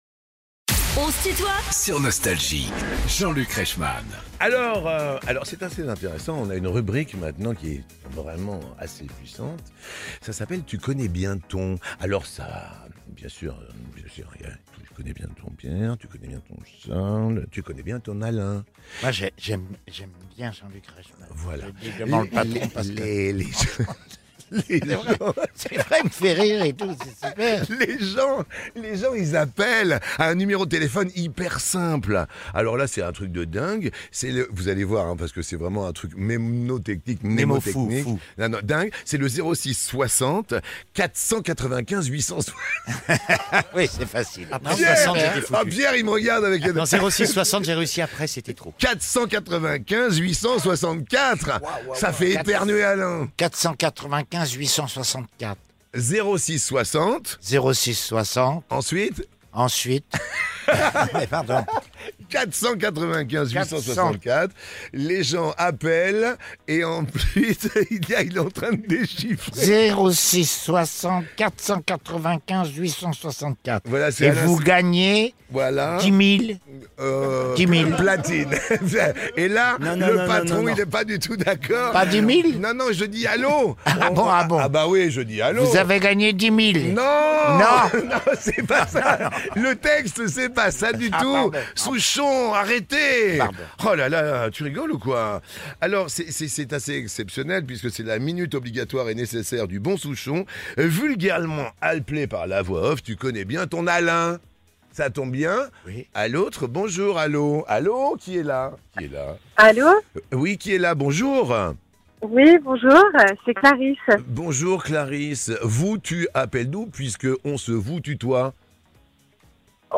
Alain Souchon et ses fils sont les invités de "On se tutoie ?..." avec Jean-Luc Reichmann
Erweiterte Suche Tu connais bien ton Alain Souchon ? 11 Minuten 10.15 MB Podcast Podcaster Les interviews Les plus grands artistes sont en interview sur Nostalgie.